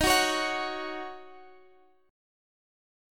Listen to Ebm7 strummed